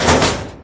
reactorStop.ogg